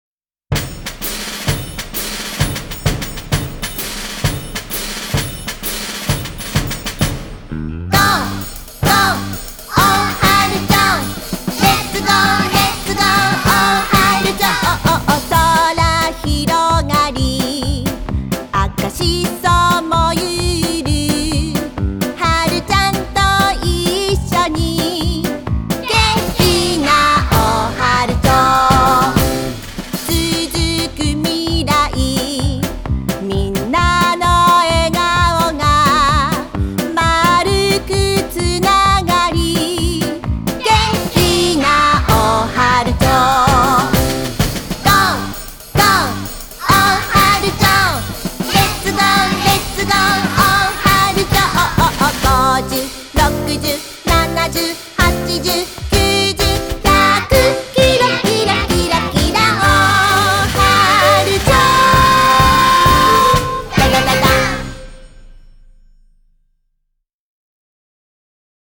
2曲とも、アップテンポで耳馴染みが良く、口ずさみたくなる楽しい曲となっています。
美しくのびやかな歌声も披露してくださっています。
コーラス参加
園児の皆さんの元気いっぱいな歌声も一緒にお聞きください。